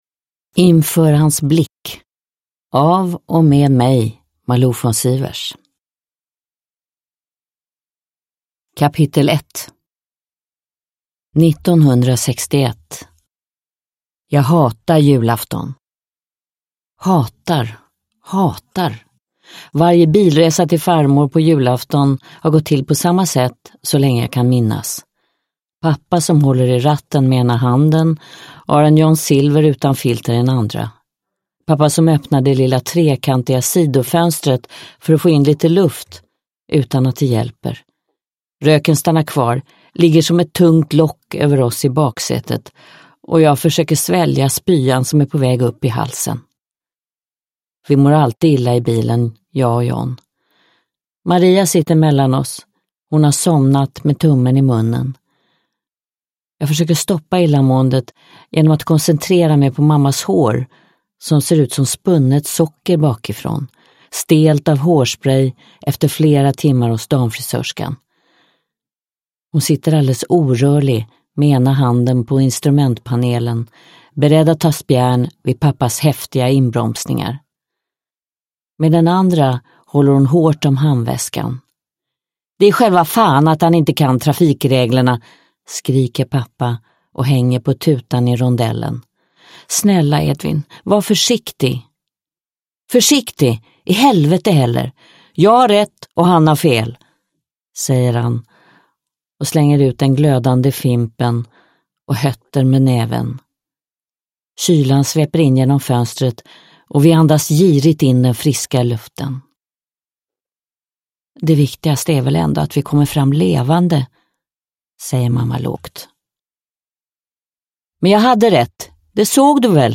Inför hans blick – Ljudbok – Laddas ner
Uppläsare: Malou von Sivers